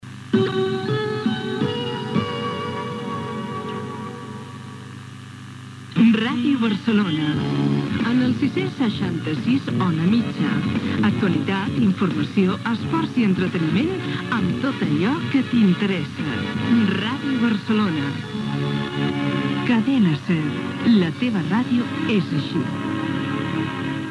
b46b814d5745c1faac1ef68f6f06e7ecc80260bc.mp3 Títol Ràdio Barcelona Emissora Ràdio Barcelona Cadena SER Titularitat Privada estatal Descripció Indicatiu de l'emissora amb la freqüència en ona mitjana.